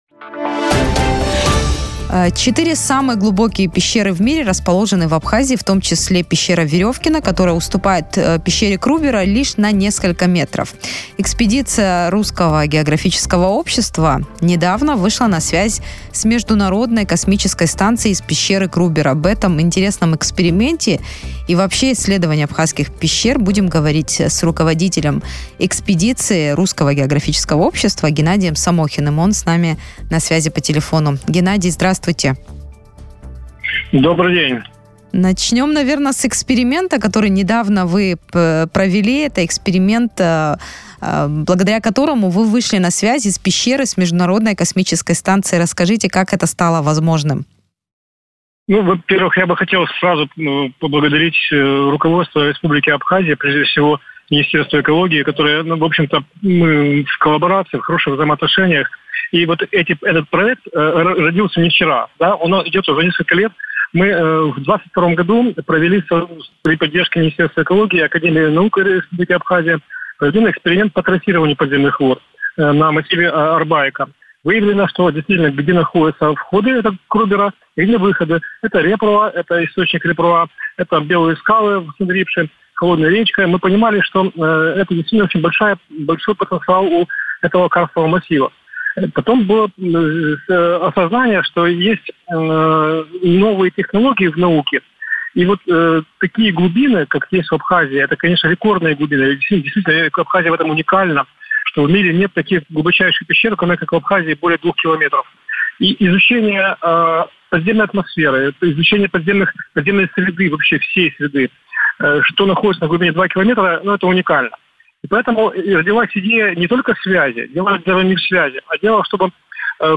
в интервью радио Sputnik